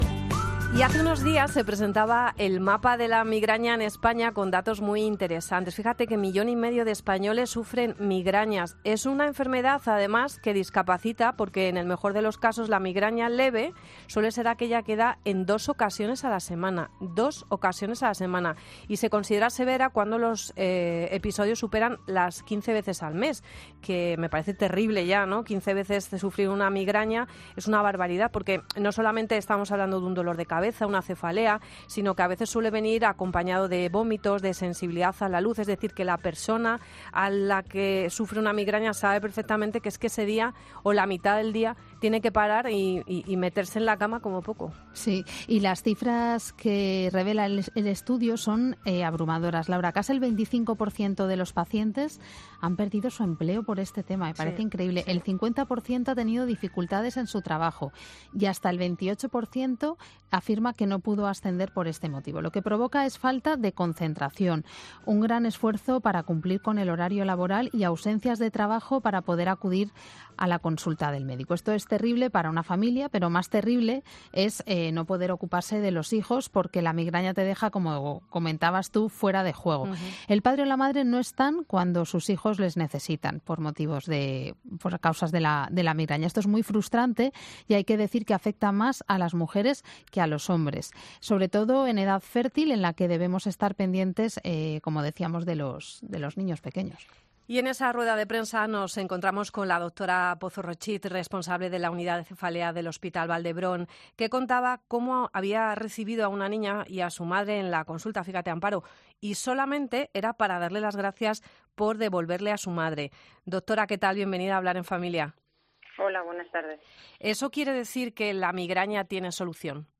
Dale al play y escucha la entrevista completa para recuperar tu vida o la de los que te importan.